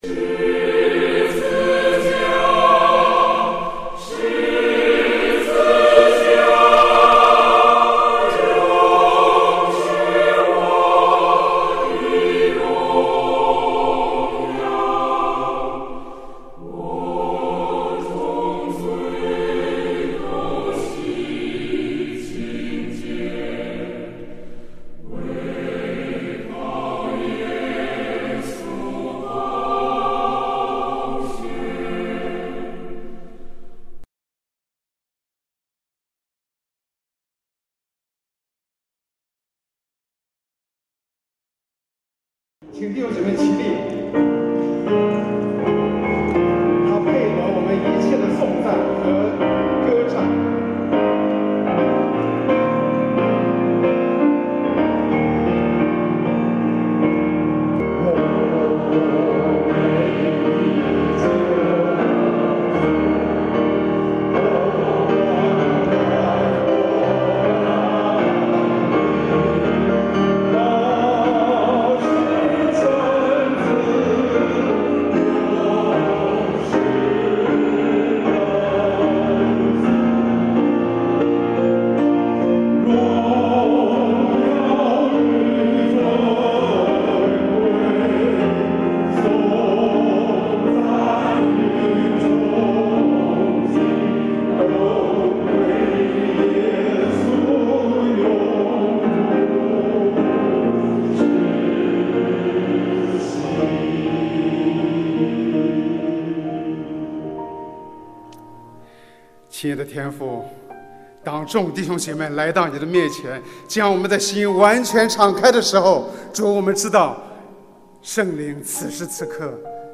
13福音大会